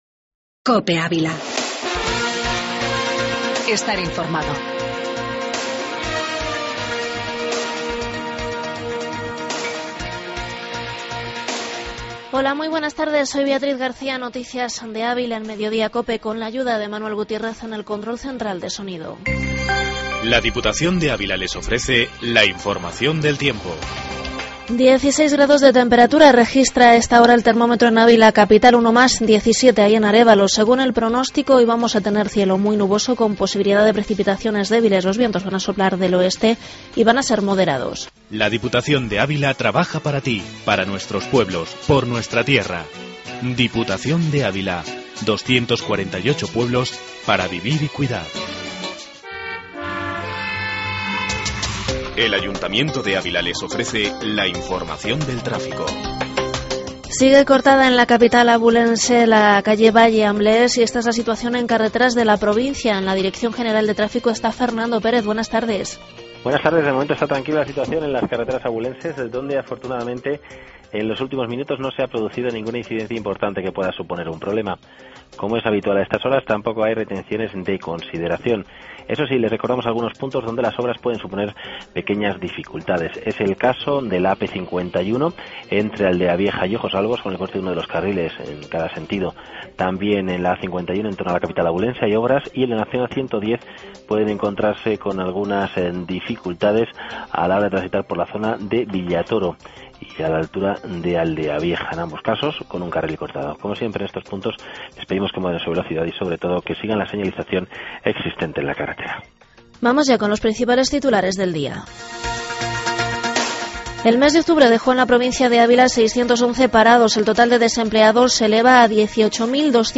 AUDIO: Información local